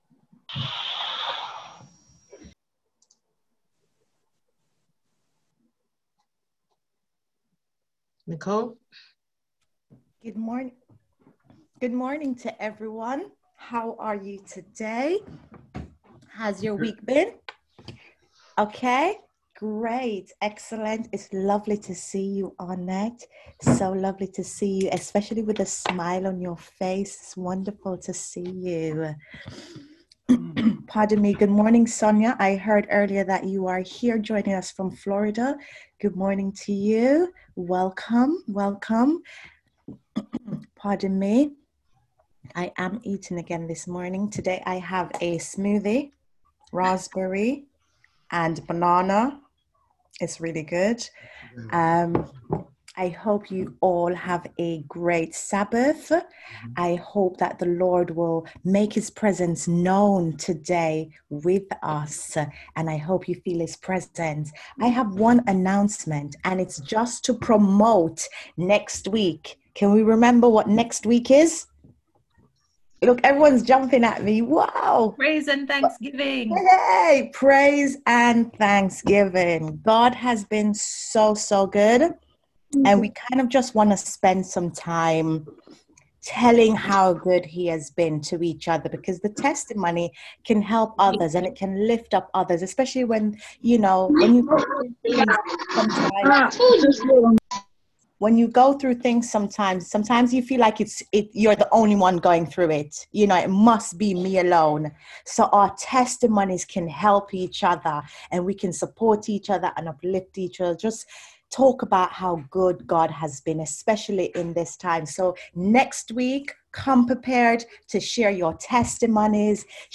on 2024-02-14 - Sabbath Sermons